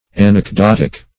Anecdotic \An`ec*dot"ic\, Anecdotical \An`ec*dot"ic*al\, a.